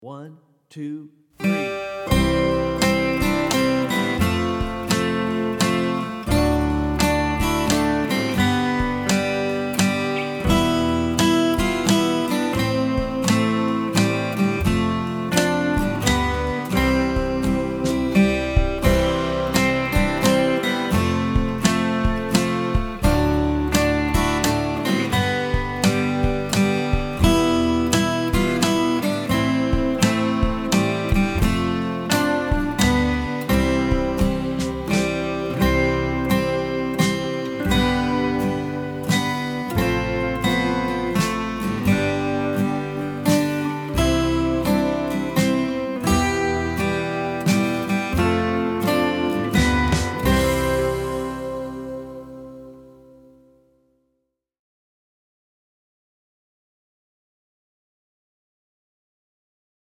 Song Demonstration – Kids Guitar Academy
We Wish You a Merry Christmas Slow Play-Along Mp3